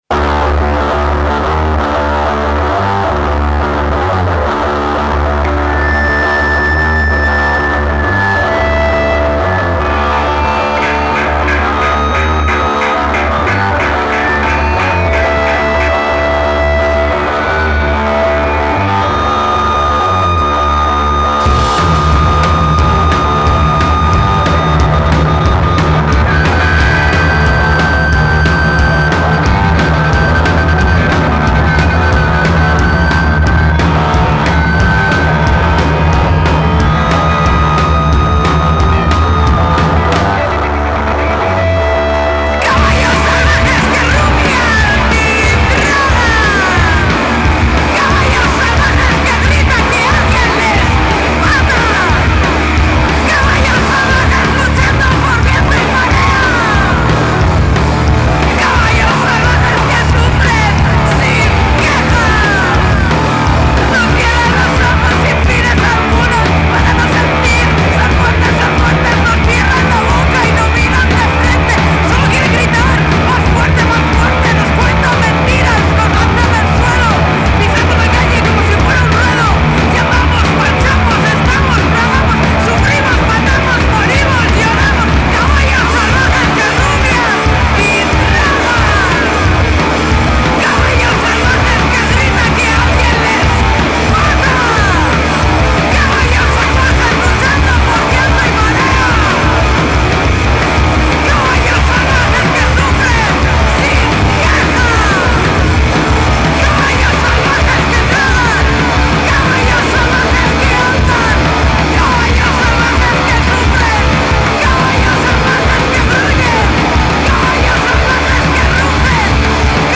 Un debut enérgico y opresivo a partes iguales
Genres: Doom, Noise, Post-Punk, Alternative Rock
Bajo/Bass
Voz/Vocals
Batería/Drums
Guitarra/Guitar